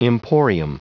Prononciation du mot emporium en anglais (fichier audio)
Prononciation du mot : emporium